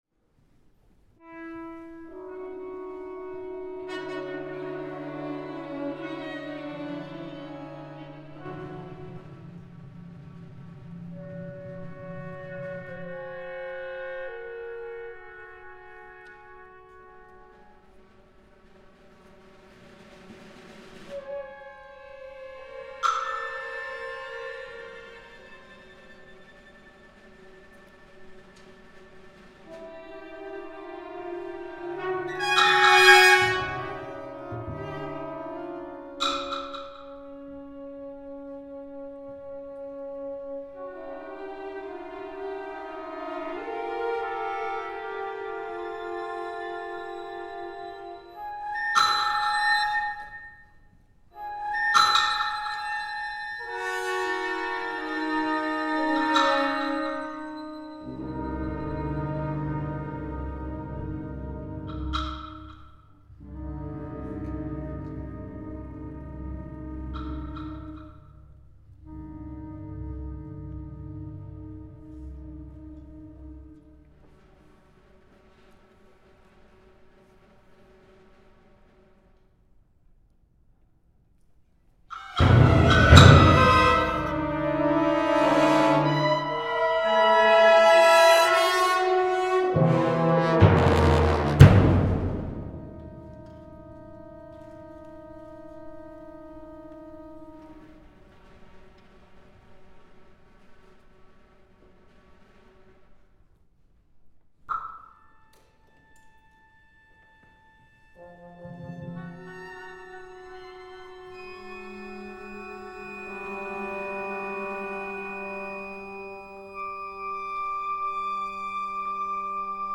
for orchestra
Princeton University, May 8, 2002